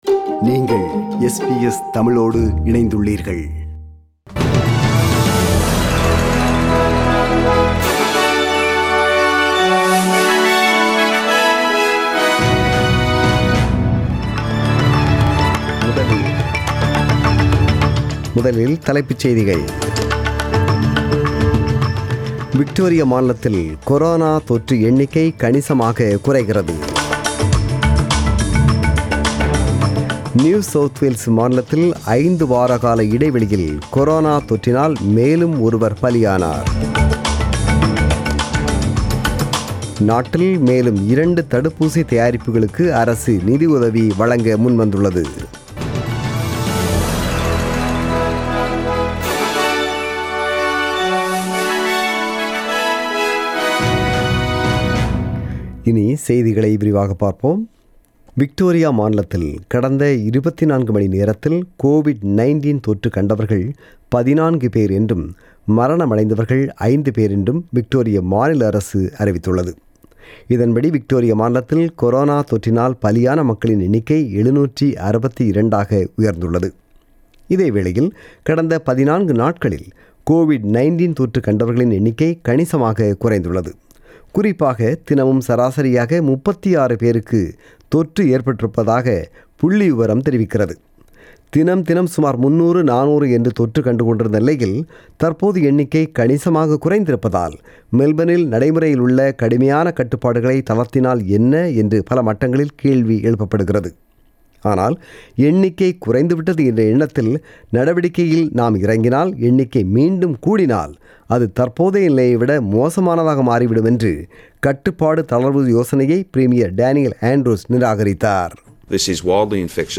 The news bulletin was broadcasted on 20 September 2020 (Sunday) at 8pm.